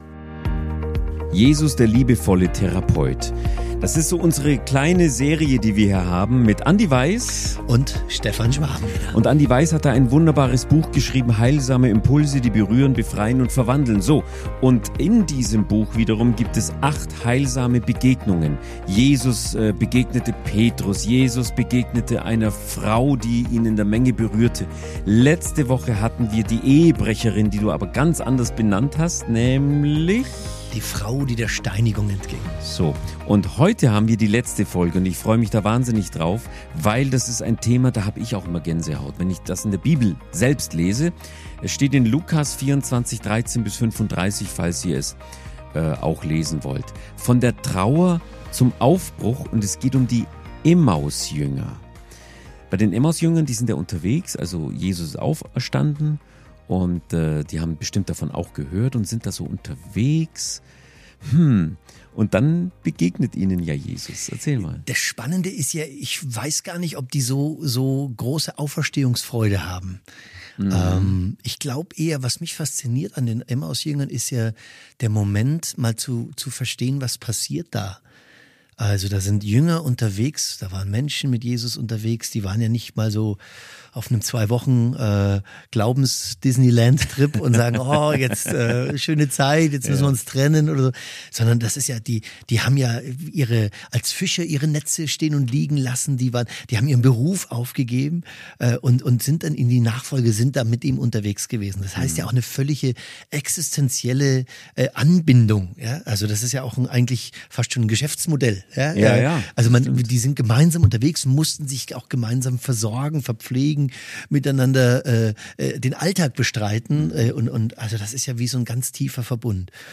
Aber ehrliche, leise, starke Worte.